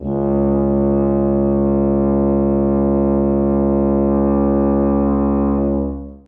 Lightningbeam / src / assets / instruments / brass / tuba / samples / C#2.mp3
C#2.mp3